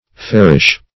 Fairish \Fair"ish\, a.